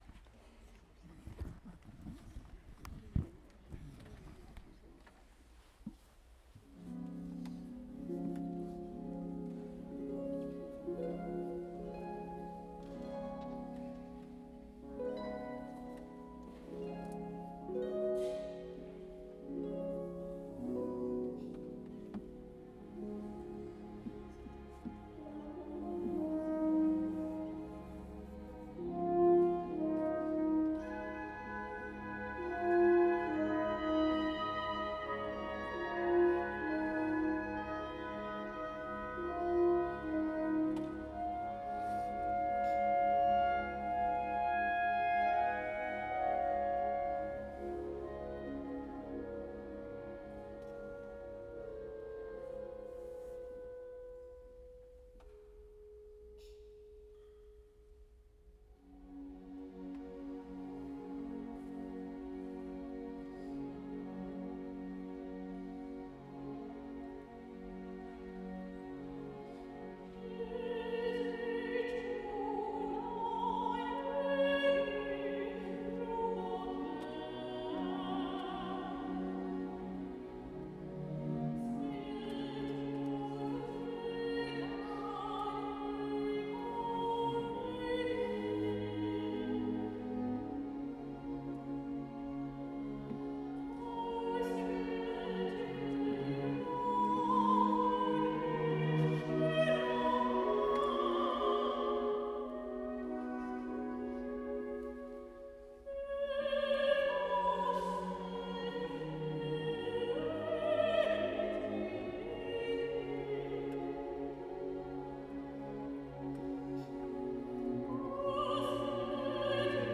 Chaque extrait audio est un instantané de nos performances live.
Extrait de concert